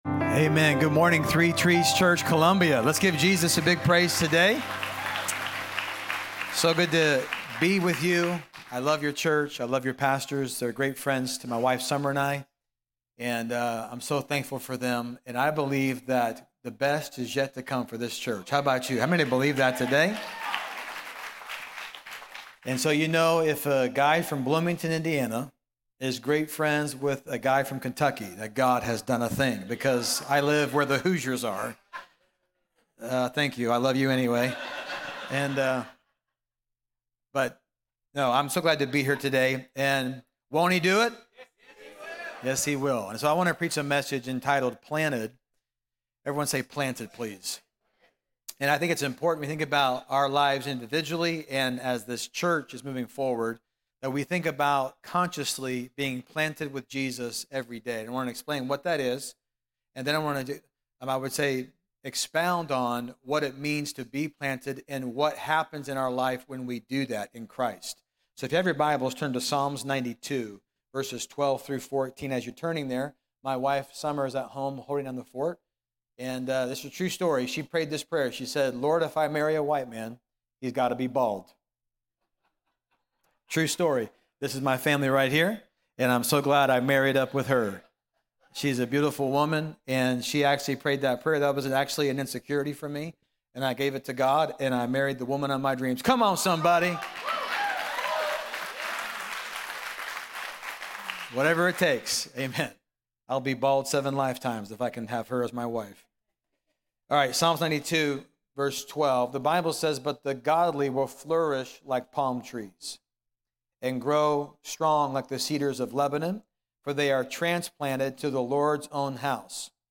Through personal stories of healing, racial reconciliation, and generational impact, Pastor shares how God turns poison into purpose and pain into praise. Whether you're in a season of suffering or searching for identity, this message will inspire you to stay rooted in Jesus and flourish in your faith.